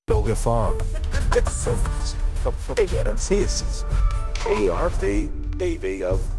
novafarma/assets/audio/voiceover/prologue/prologue_19.wav